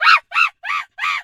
monkey_2_scream_05.wav